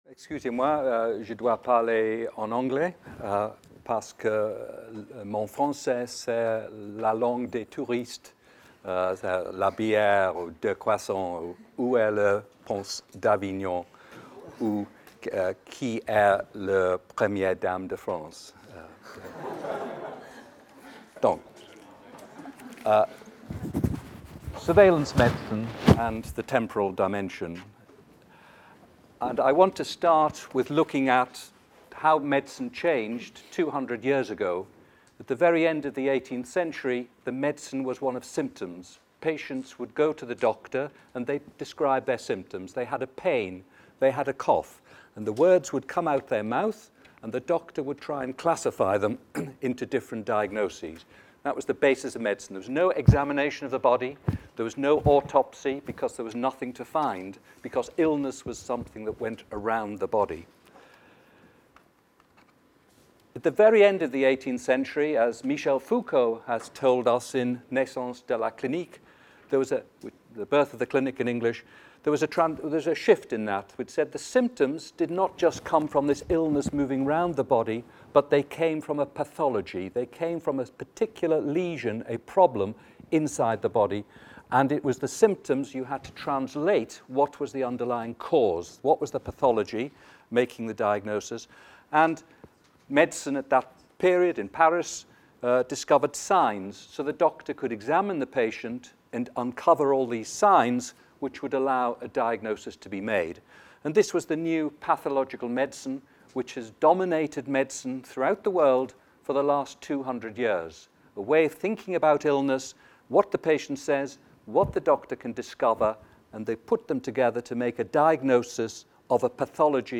Medical surveillance and the temporal dimension Intervention au séminaire formes de surveillance en médecine et santé publique.